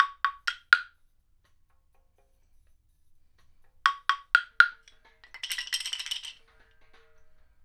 126-PERC2.wav